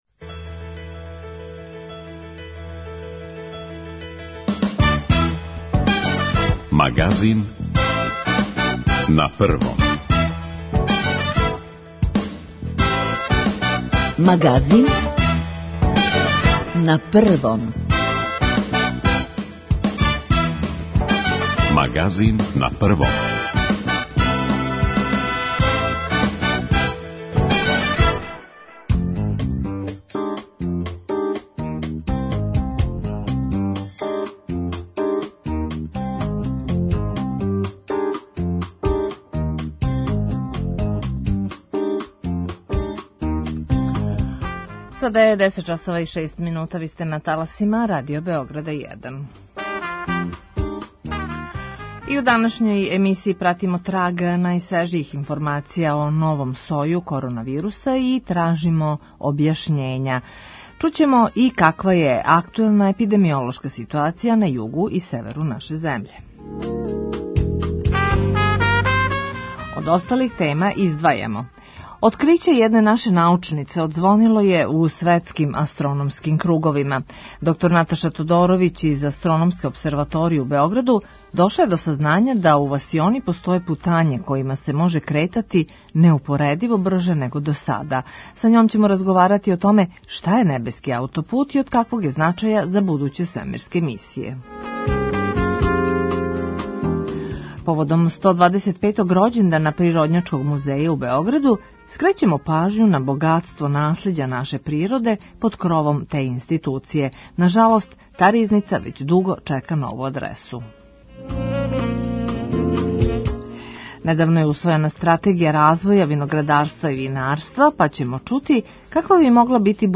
У Магазину пратимо траг најновијих информација и тражимо објашњења. преузми : 31.41 MB Магазин на Првом Autor: разни аутори Животне теме, атрактивни гости, добро расположење - анализа актуелних дешавања, вести из земље и света.